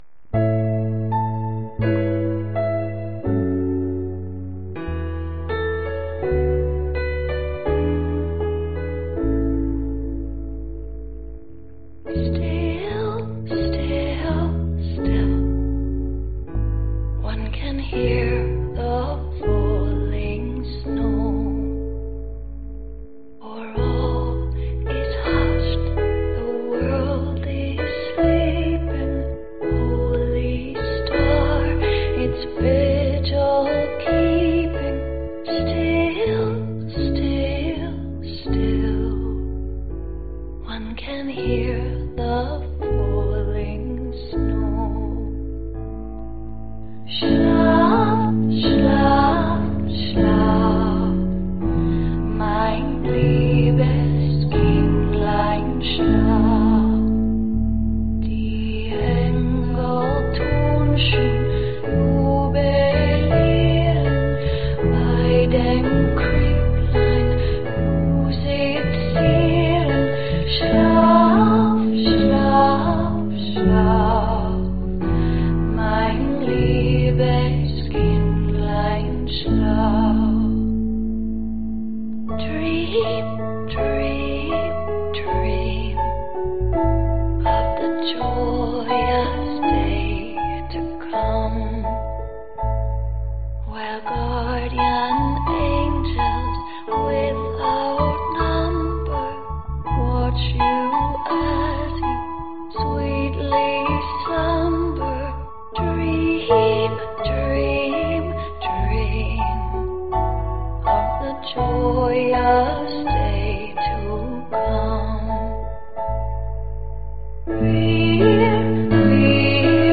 标签： male_vocals guitar bass piano organ drums
声道立体声